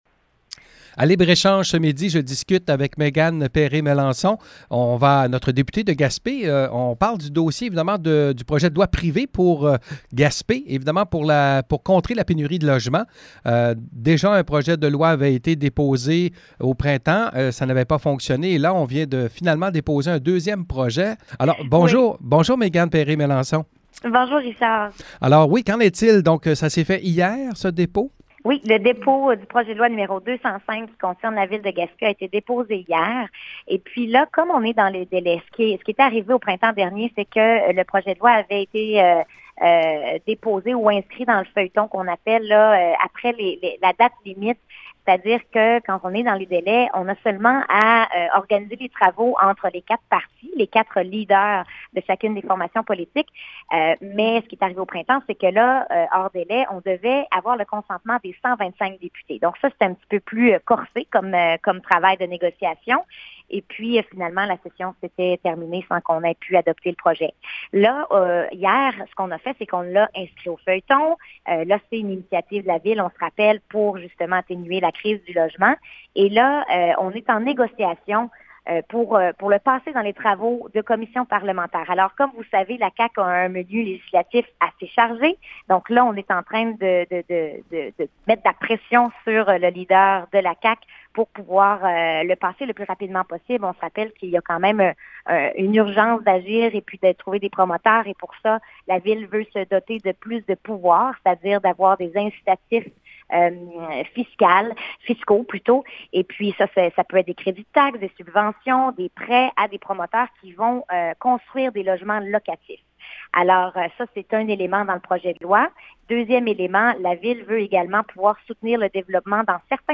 Entrevue avec la députée de Gaspé: